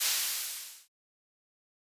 steam hisses - Marker #2.wav